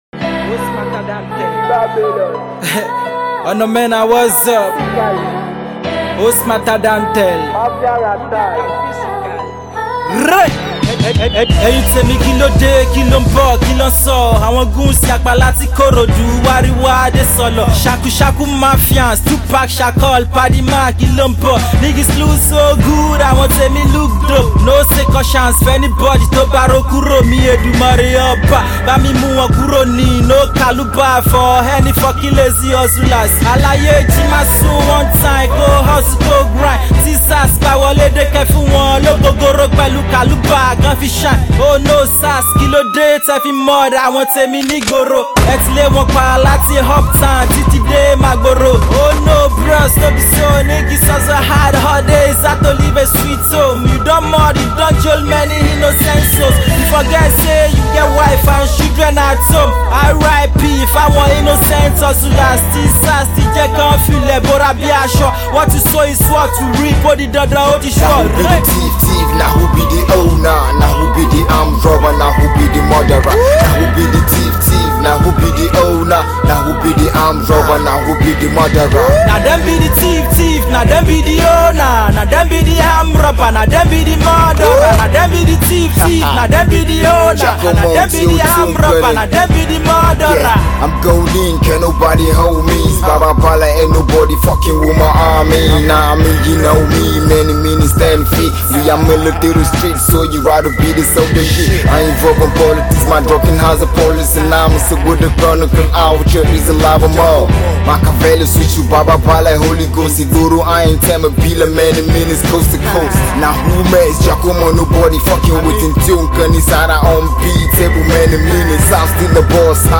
The street anthem